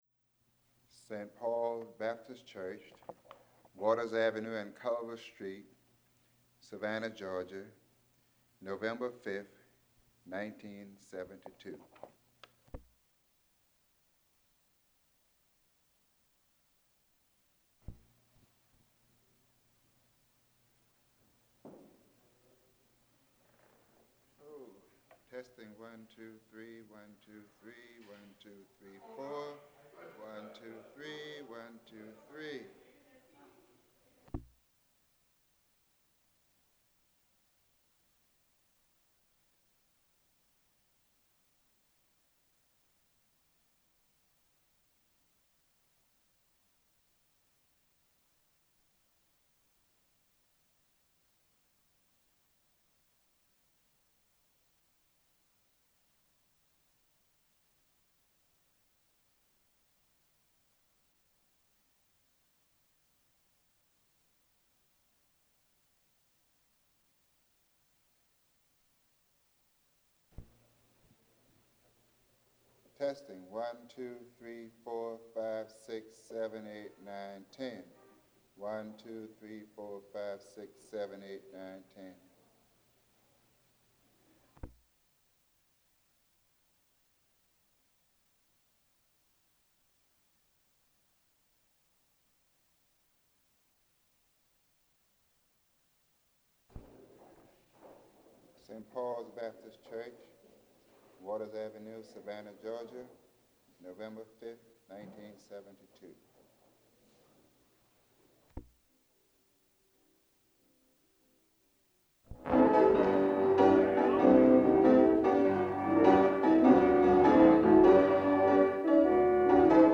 Object Name Tape, Magnetic Source W. W. Law Foundation Credit line Courtesy of City of Savannah Municipal Archives Copyright Copyright has not been assigned to the City of Savannah.